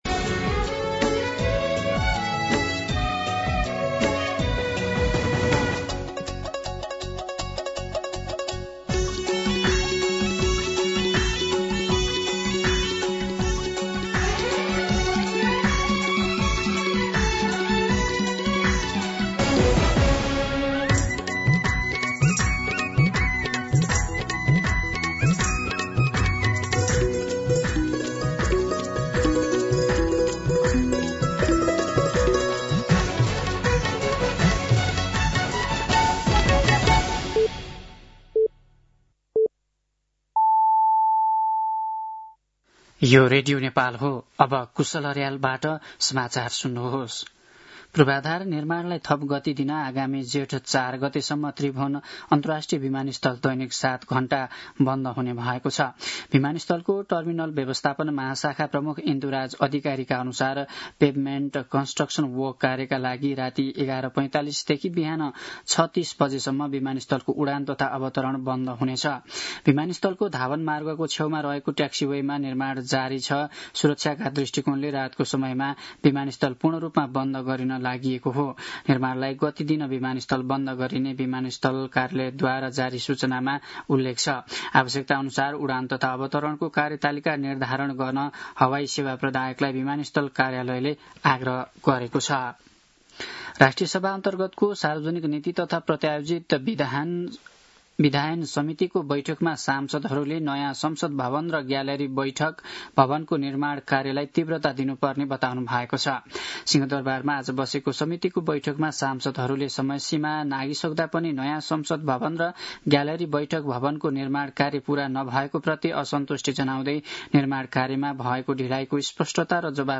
दिउँसो ४ बजेको नेपाली समाचार : ८ वैशाख , २०८३
4pm-News-08.mp3